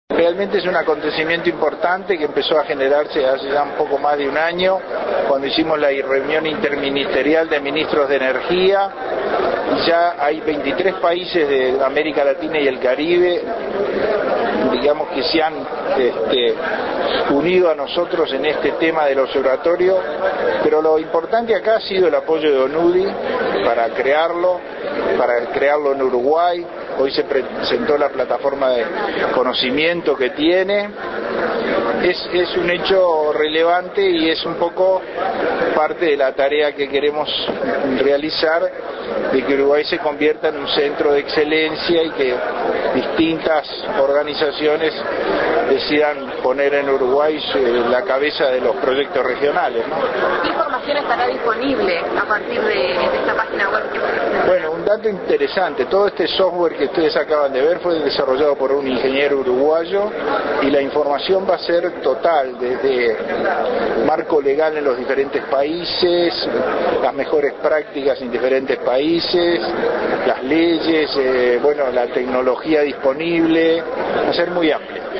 Palabras del Ministro de Industria, Energía y Minería, Jorge Lepra, en el acto de inauguración del primer Observatorio de Energía Renovable en Uruguay.